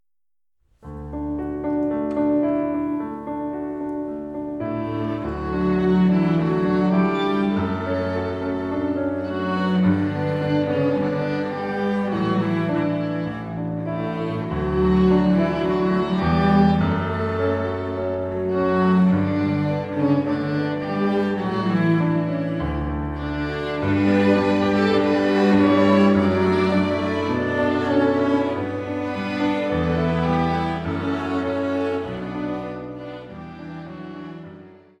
Streichensemble, Klavier